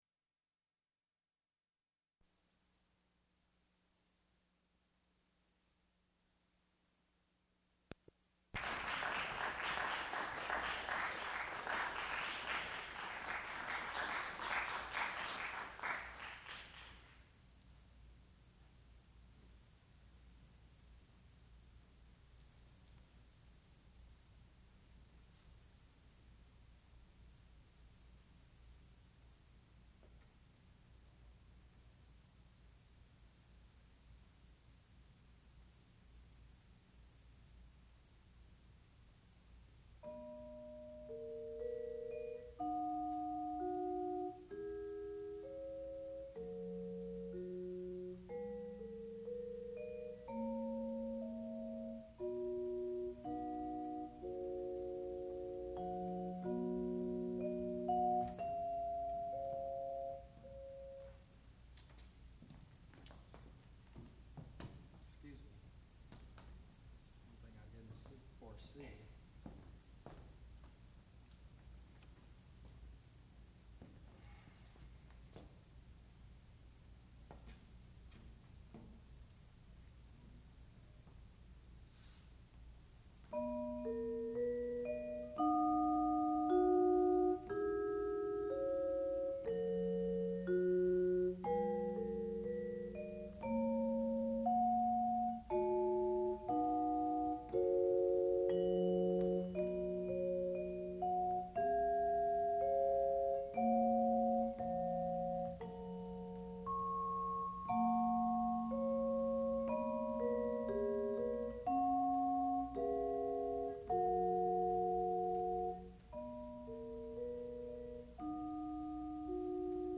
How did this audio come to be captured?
Junior Recital